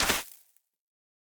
Minecraft Version Minecraft Version 1.21.5 Latest Release | Latest Snapshot 1.21.5 / assets / minecraft / sounds / block / suspicious_sand / step2.ogg Compare With Compare With Latest Release | Latest Snapshot
step2.ogg